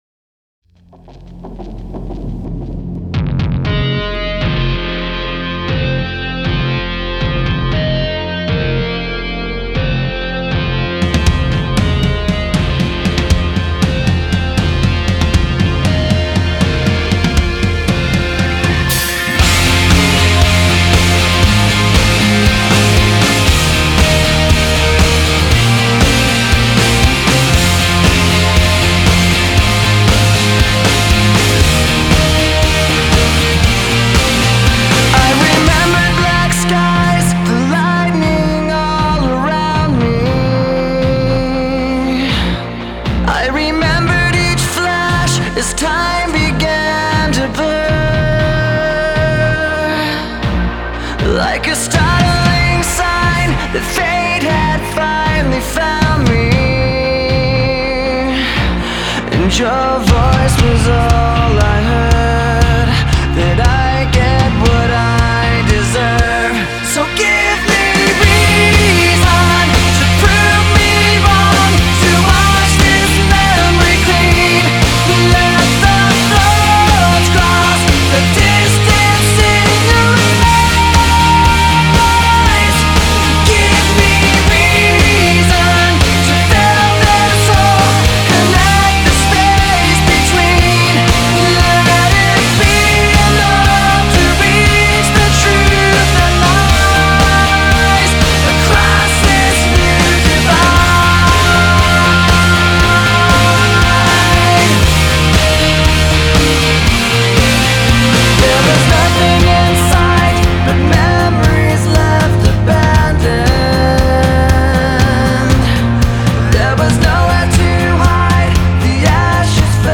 Категория: Рок, Альтернатива